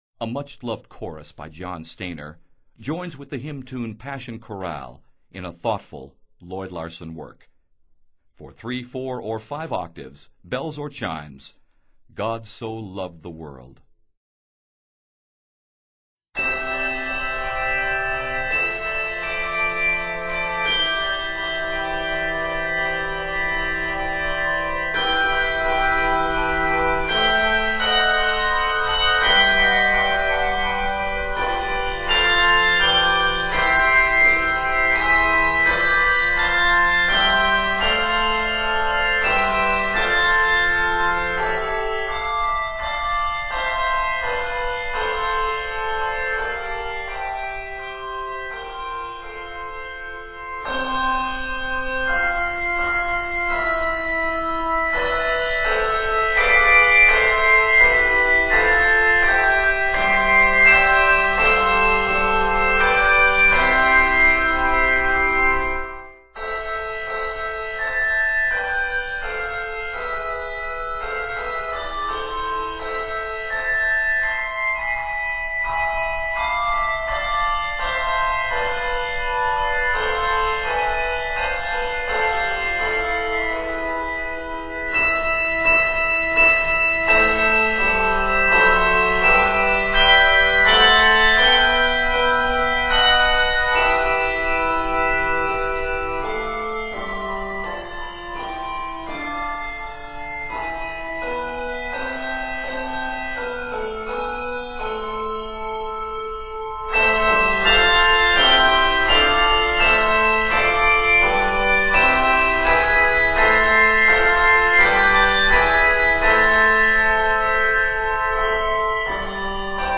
handbell music